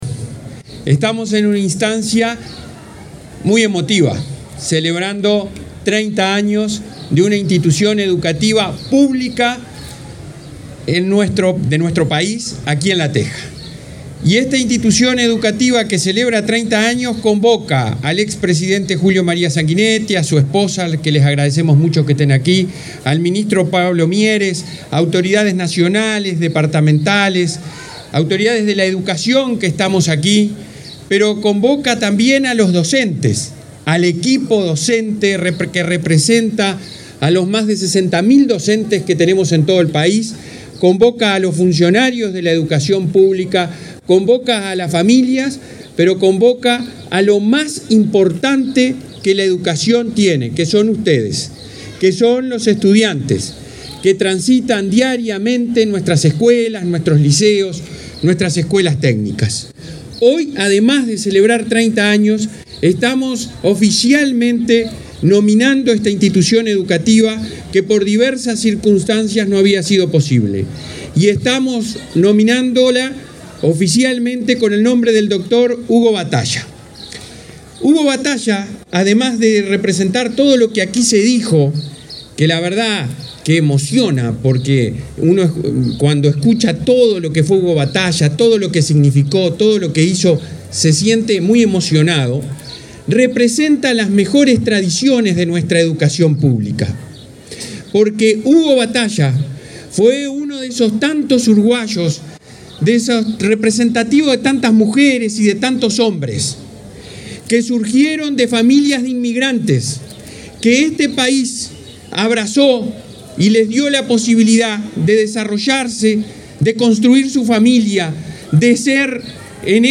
Palabras del presidente de la ANEP, Robert Silva
La ANEP realizó este viernes 21 un acto oficial por la denominación del liceo n.º 47, de La Teja, en Montevideo, como "Dr. Hugo Batalla".